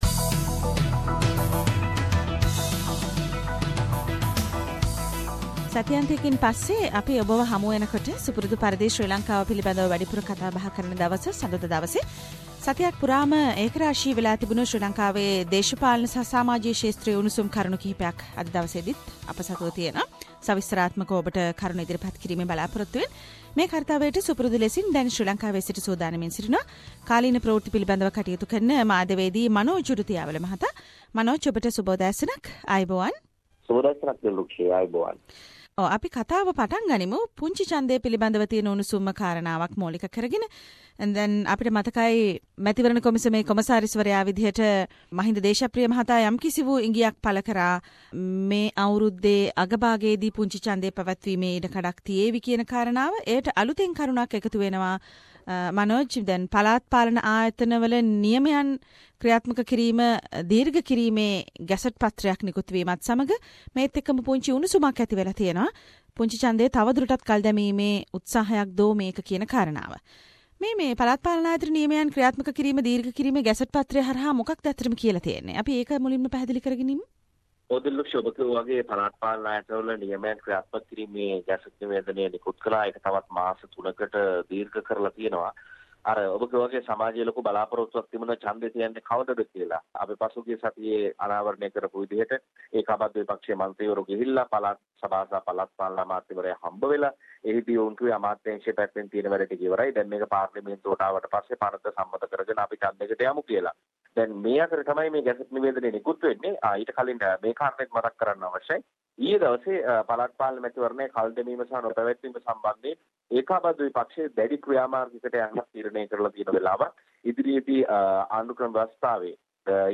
reports from Sri Lanka